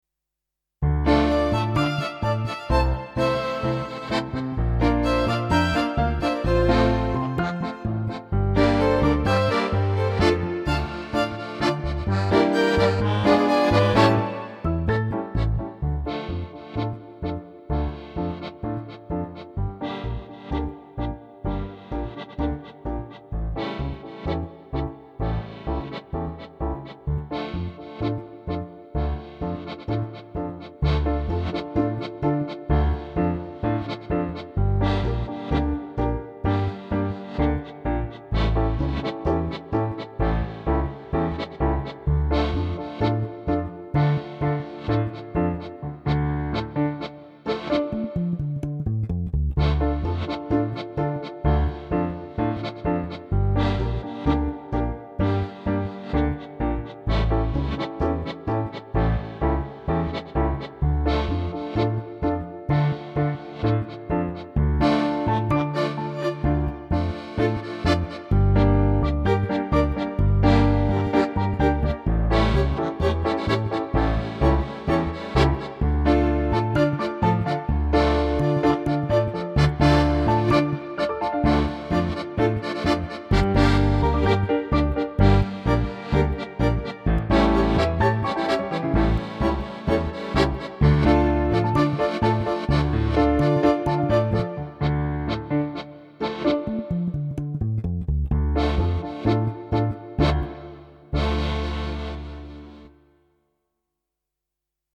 Home > Music > Latin > Romantic > Elegant > Medium Fast